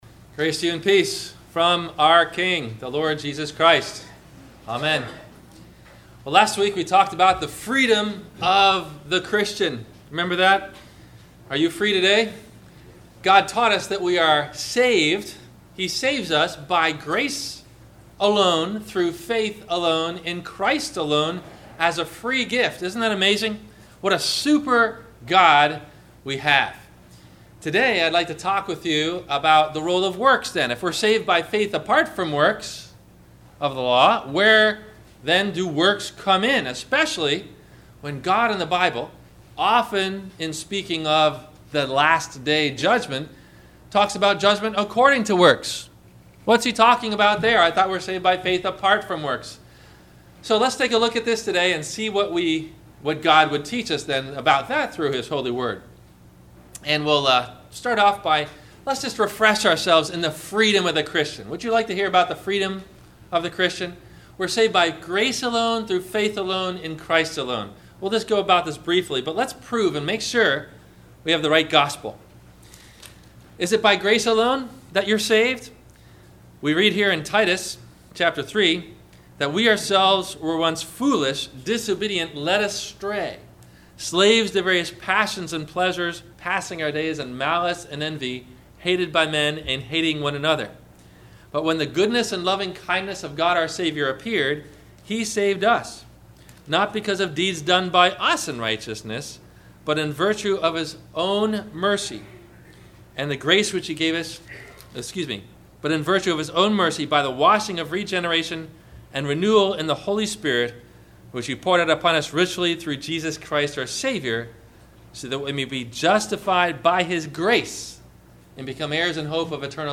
Will God Judge Us By Our Works? – Sermon – January 31 2016